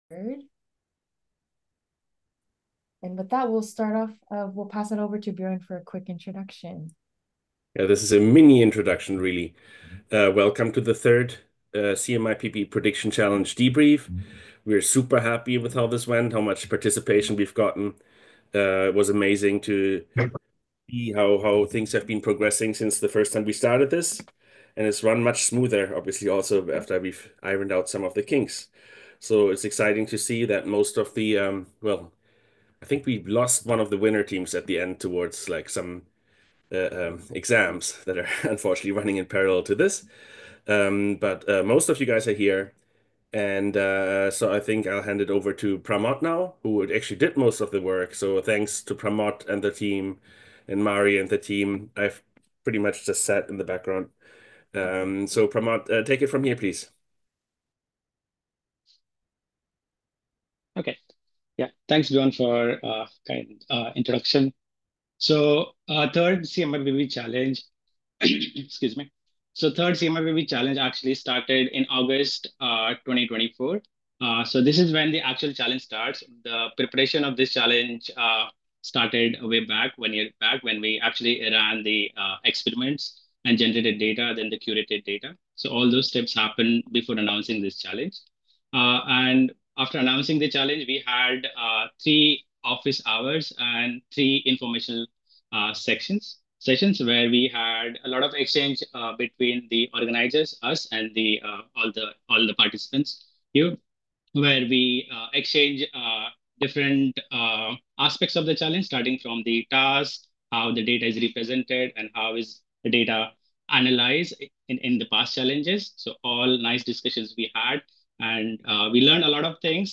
Full Debrief Session Recording - March 14th, 2025